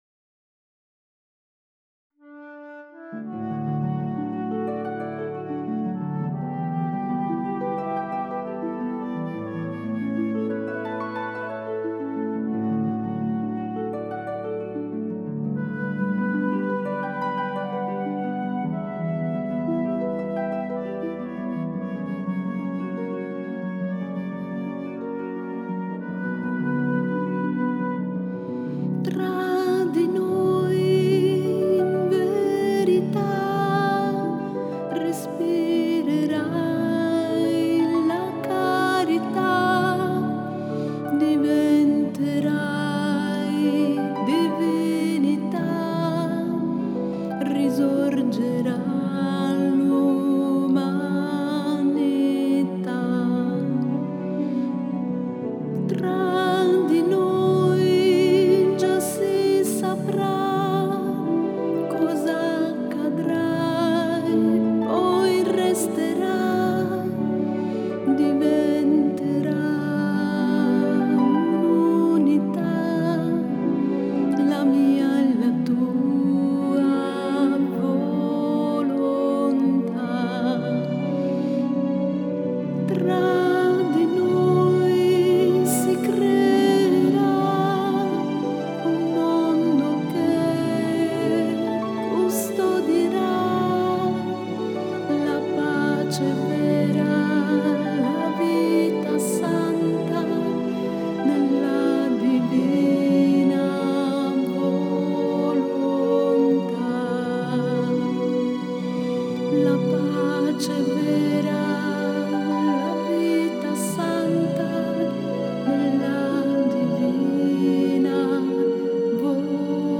Canto: Tra di noi